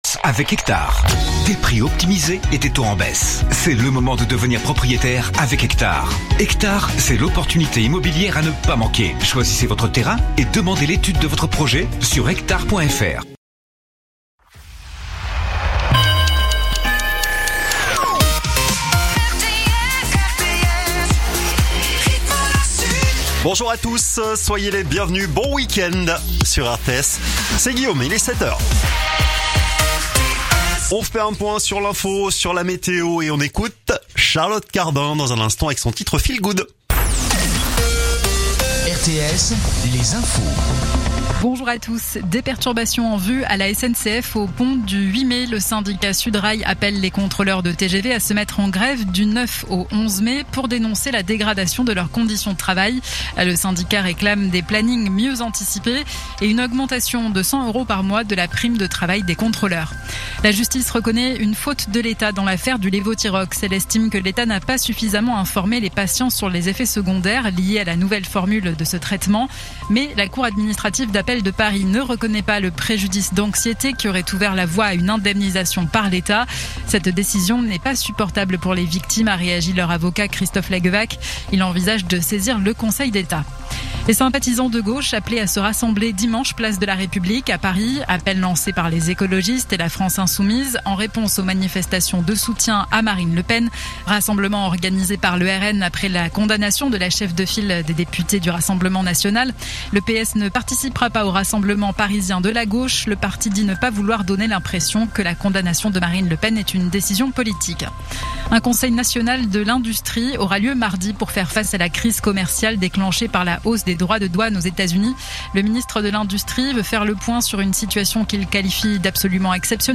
Écoutez les dernières actus de l'Hérault en 3 min : faits divers, économie, politique, sport, météo. 7h,7h30,8h,8h30,9h,17h,18h,19h.